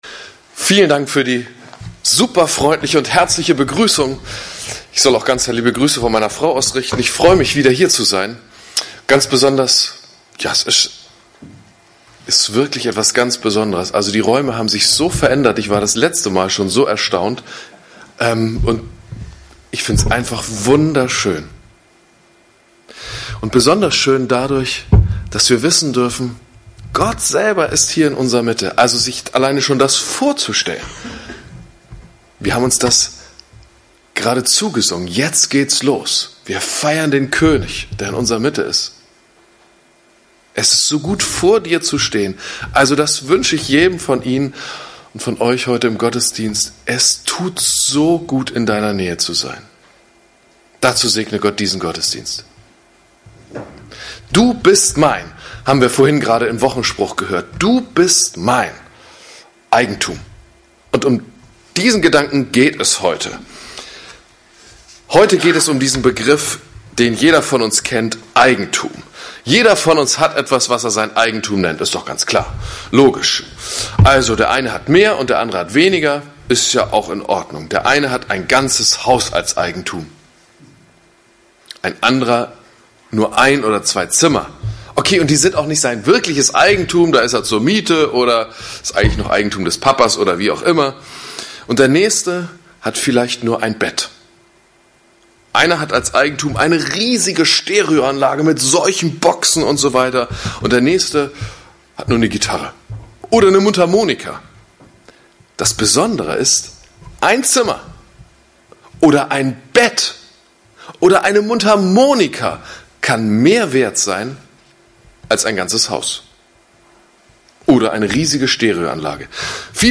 Predigt vom 16. Juli 2017